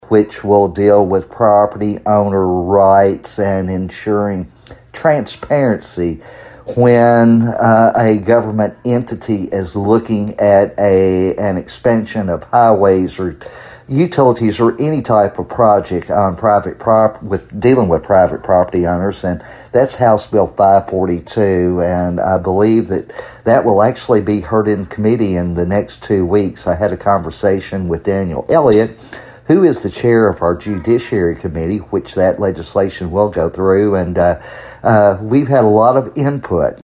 Ninth District State Representative Myron Dossett says it will provide an improved process for government acquisition of private property.